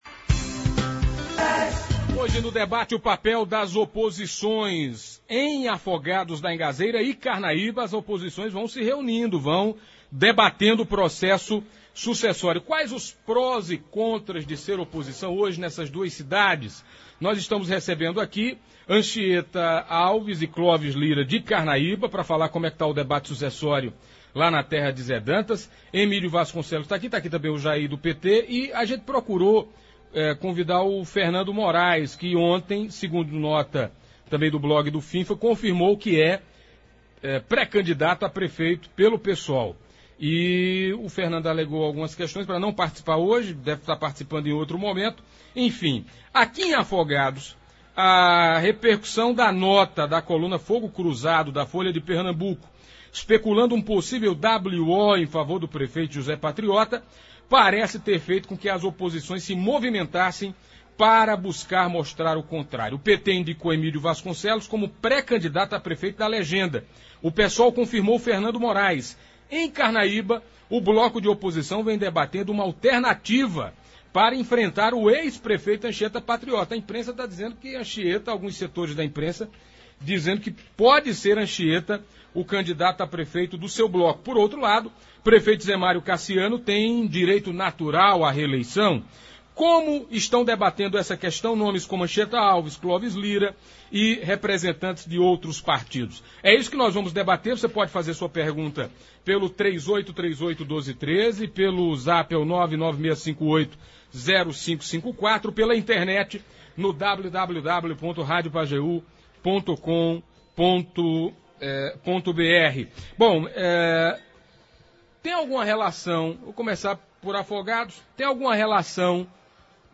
Os representantes das oposições em Afogados da Ingazeira e Carnaíba estiveram no Debate das Dez do programa Manhã Total.